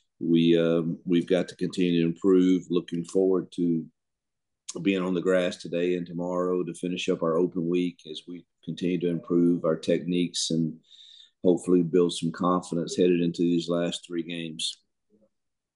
Head coach Hugh Freeze spoke on what his team’s mentality is as they look to regroup from the loss.